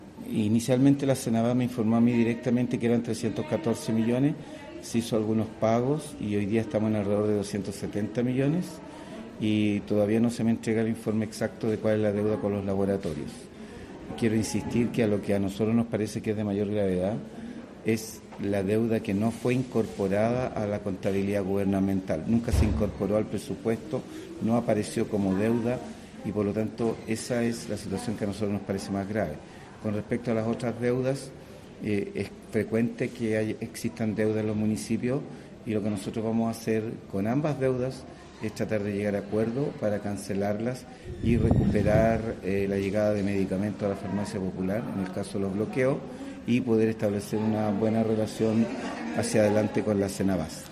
Luis-Mella.mp3